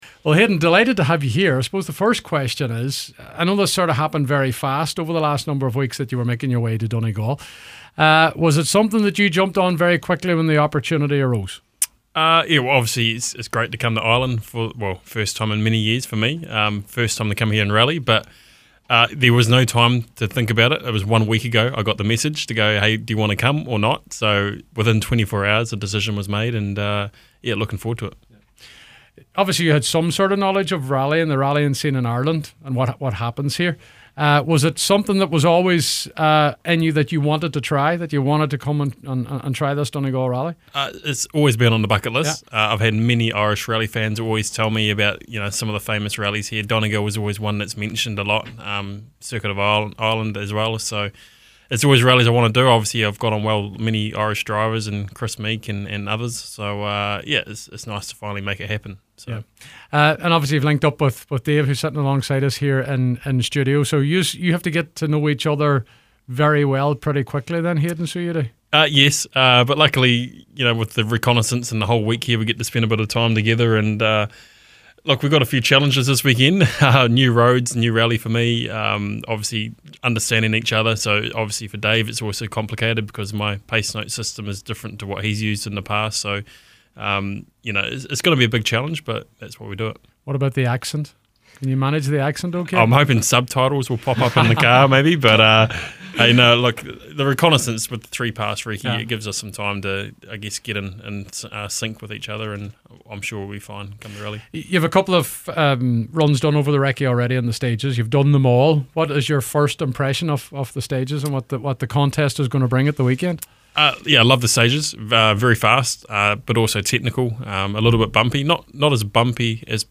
call into the studios of Highland